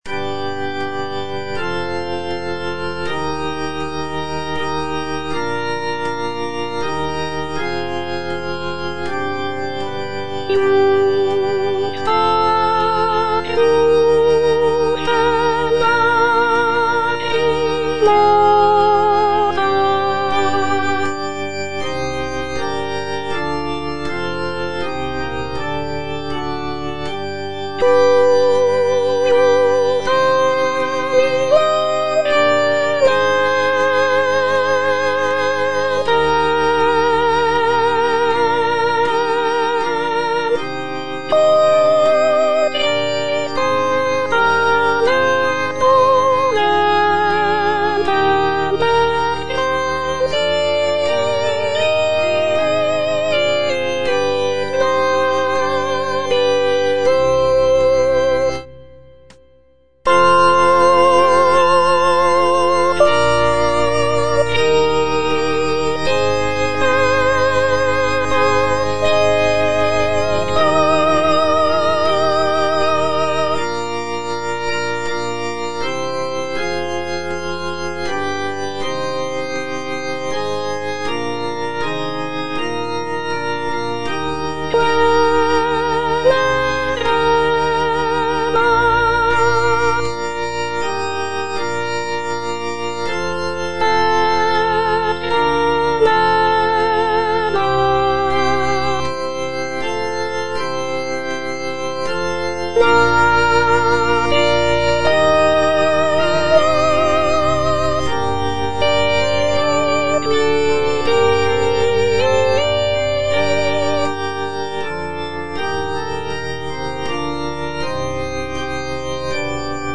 G.P. DA PALESTRINA - STABAT MATER Stabat Mater dolorosa (soprano II) (Voice with metronome) Ads stop: auto-stop
sacred choral work